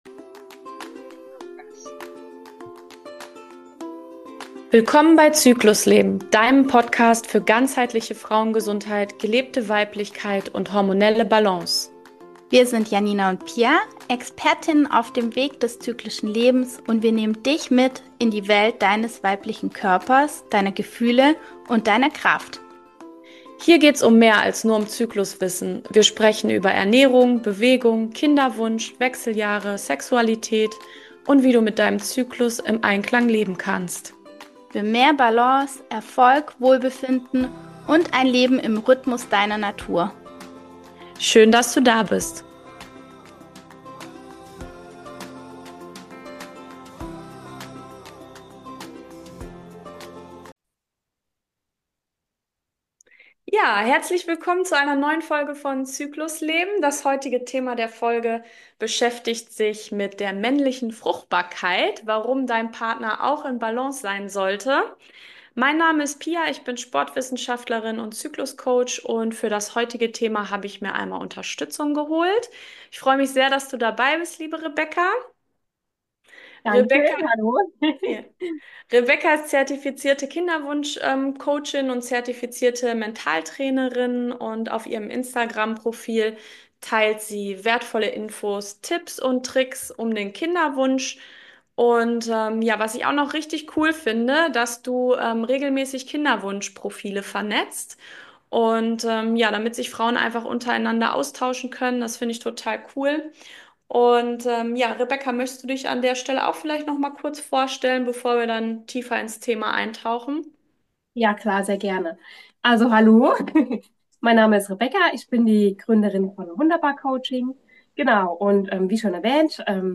Ein Gespräch, das dich stärkt – und deinen Partner einlädt, Teil des Weges zu sein.